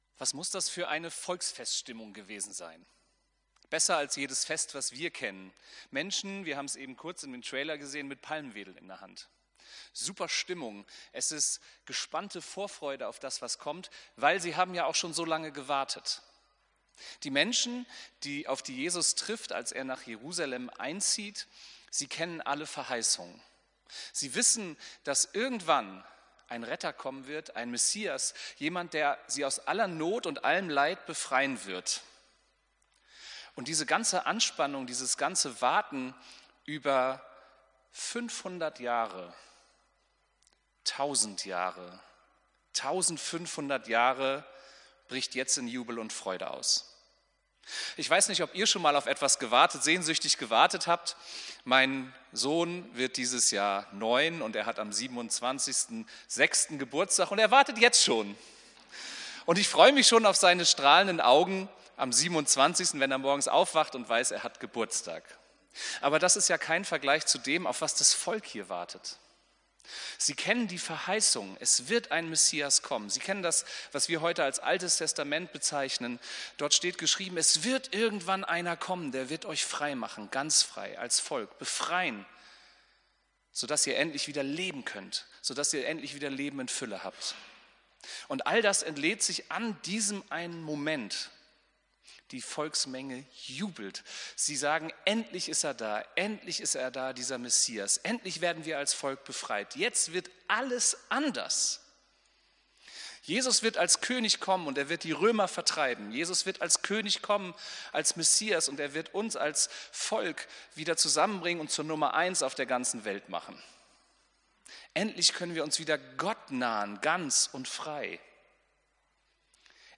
Predigt vom 13.04.2025